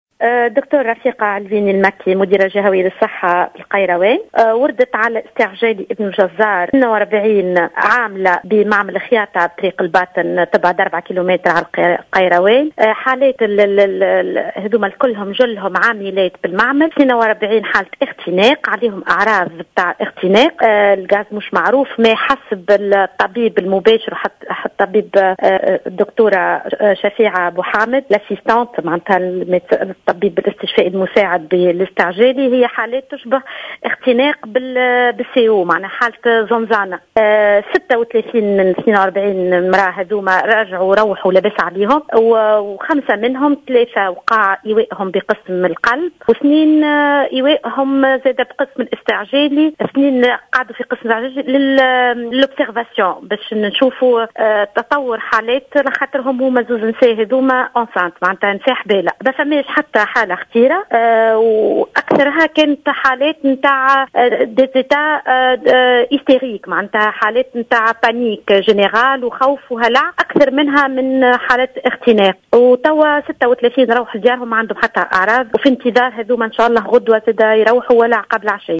أكدت المديرة الجهوية للصحة بالقيروان رفيقة علويني في تصريح للجوهرة "اف ام" أنه تم اسعاف 42 عاملة إلى المستشفى الجهوي ابن الجزار بعد إصابتهم بحالات اختناق .